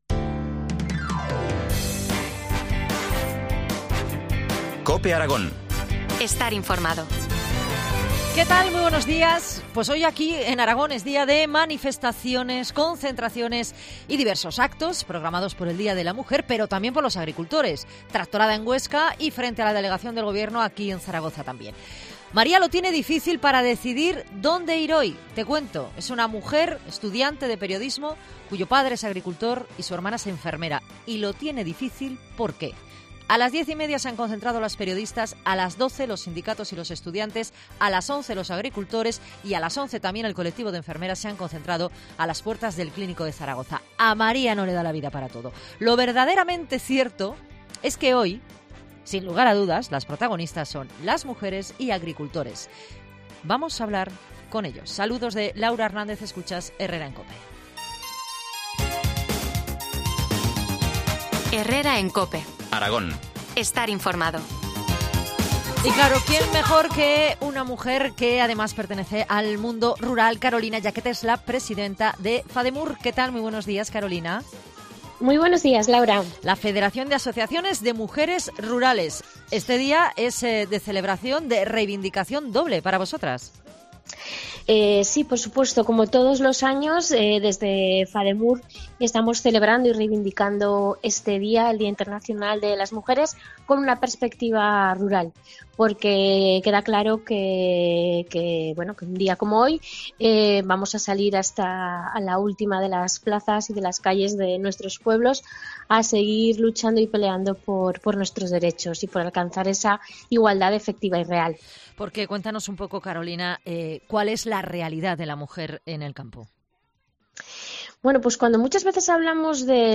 Entrevista del día en COPE Aragón